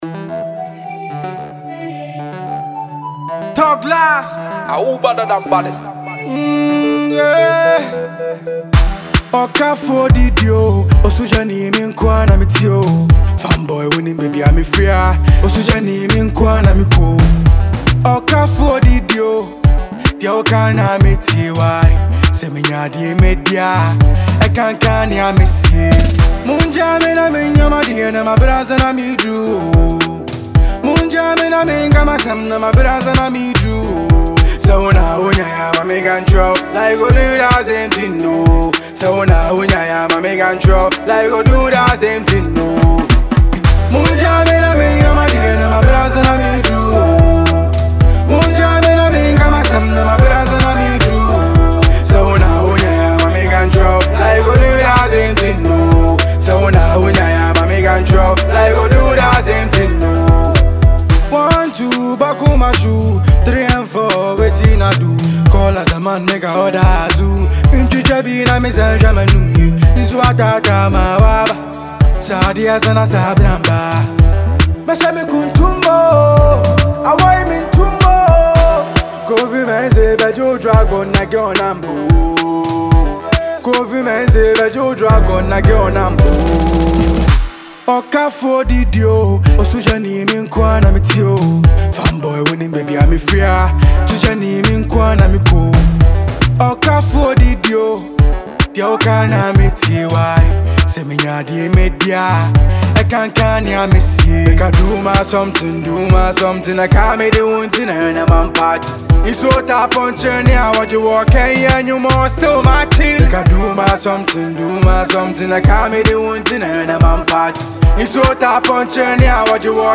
a Ghanaian young rapper